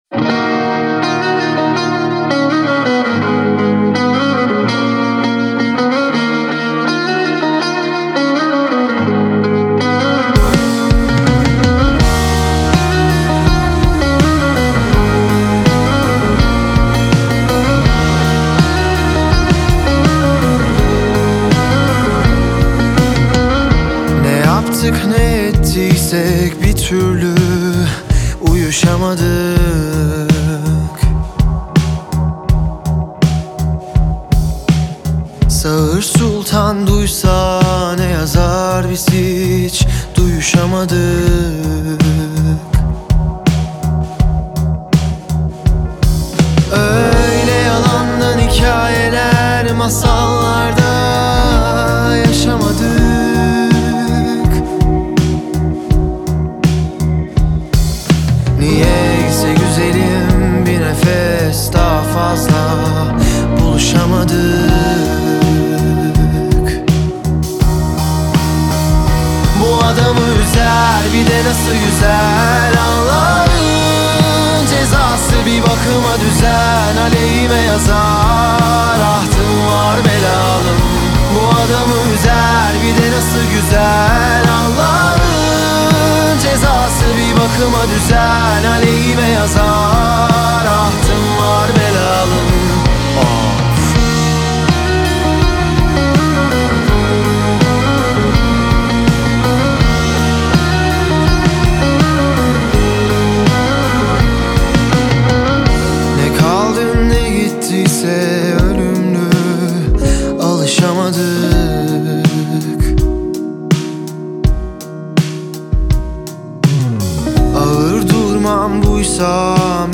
Грустные
Трек размещён в разделе Турецкая музыка / Рок.